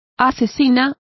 Complete with pronunciation of the translation of murderesses.